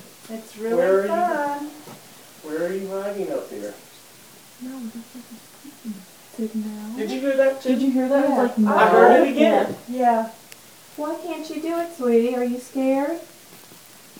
Here’s one EVP recorded during an overnight investigation in November 2011. Several of us heard an unworldly voice respond to one of our guests while up in the attic.
EVP: Female sprit in attic
girl-in-attic.wav